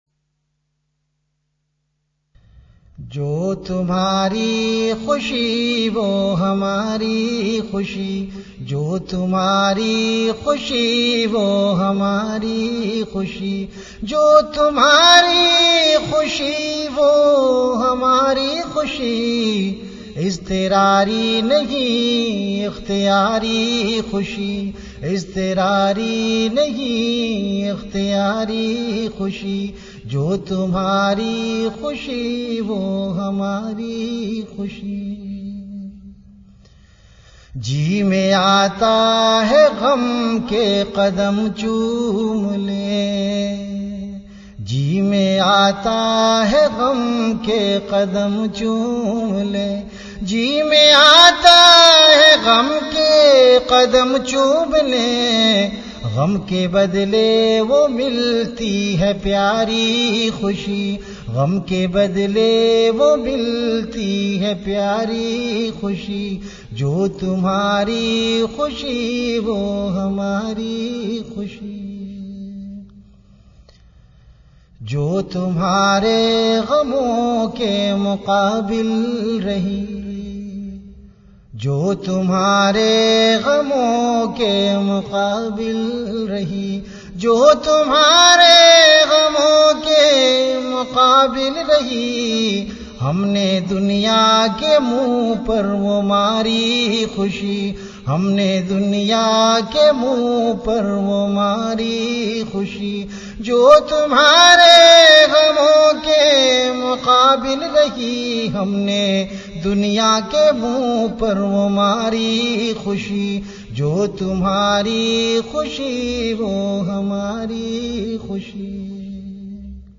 CategoryAshaar
VenueKhanqah Imdadia Ashrafia
Event / TimeAfter Isha Prayer
His unique style, coupled with his soulful voice, has inspired thousands of seekers worldwide.